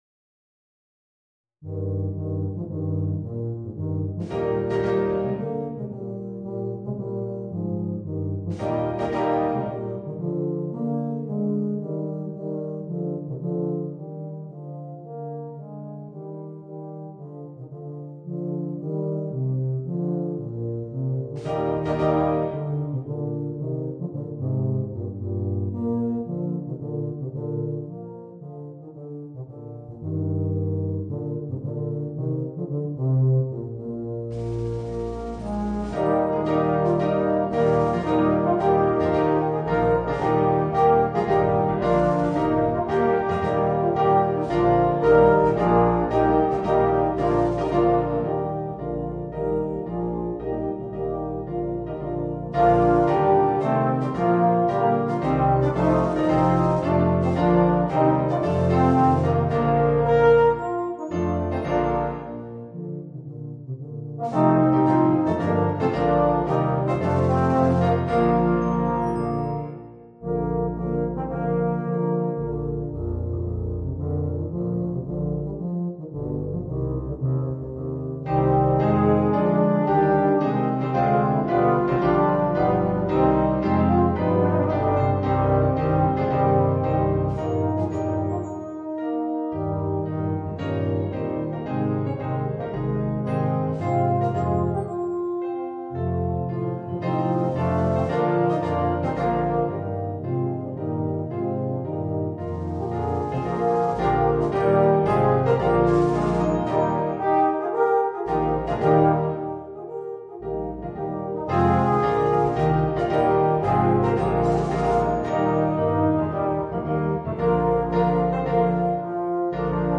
Voicing: 2 Baritones, 2 Euphoniums, 4 Tubas